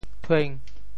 潮州 tuêng5 文 对应普通话: chuán ①转（zhuǎn）授，递交：～递 | ～输 | ～销（通过熟人宣传介绍，递相推销产品） | ～染（因接触或由其他媒介而感染疾病） | ～戒 | ～统。
thueng5.mp3